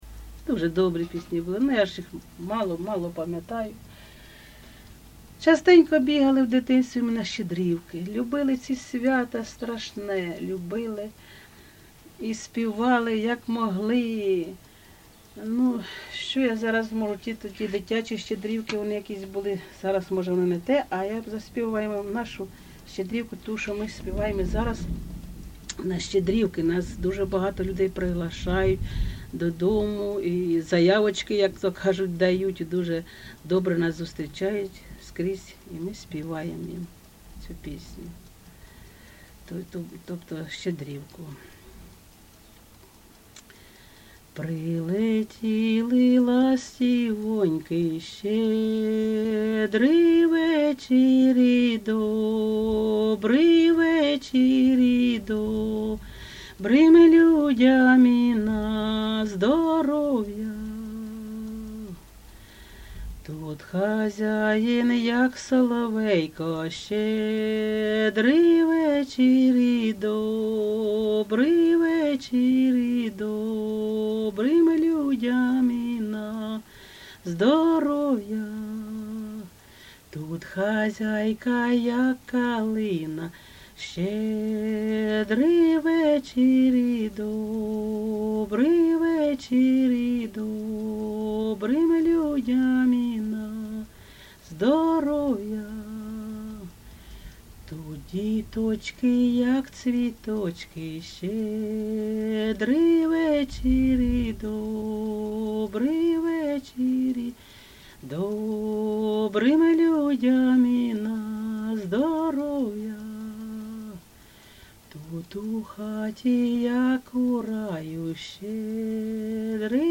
ЖанрЩедрівки
Місце записус. Серебрянка, Артемівський (Бахмутський) район, Донецька обл., Україна, Слобожанщина